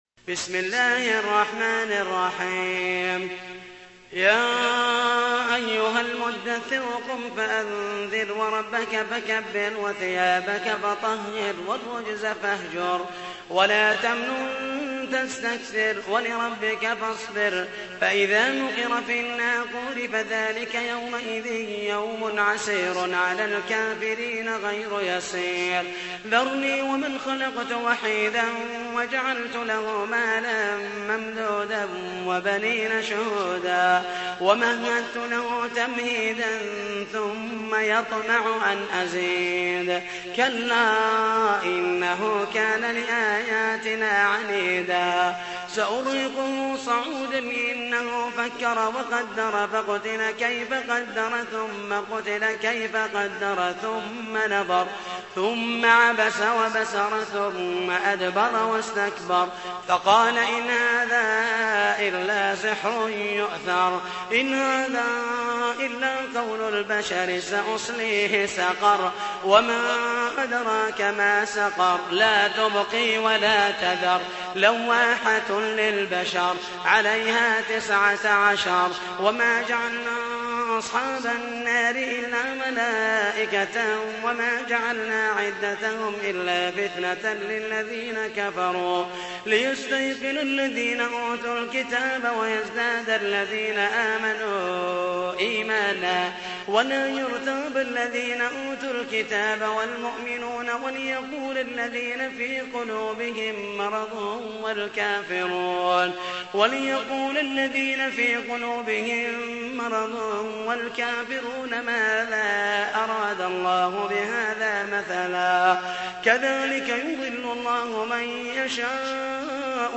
تحميل : 74. سورة المدثر / القارئ محمد المحيسني / القرآن الكريم / موقع يا حسين